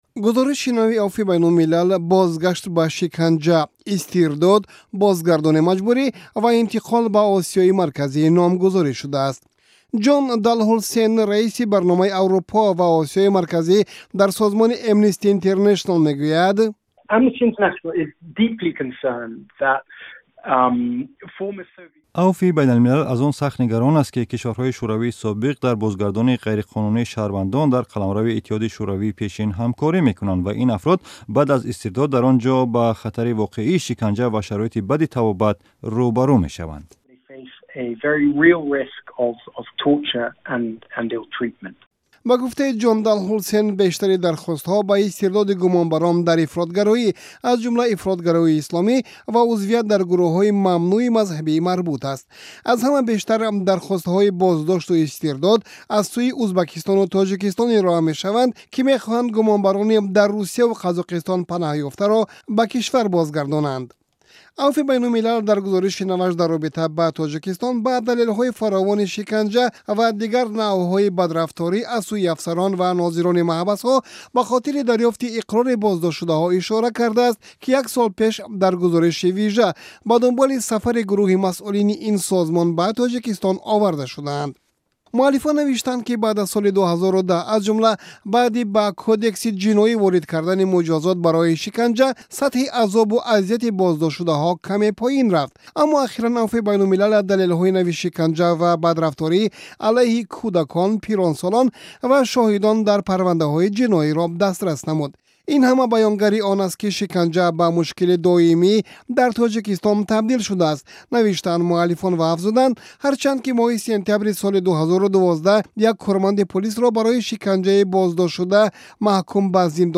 Гузориши Афви байналмилалӣ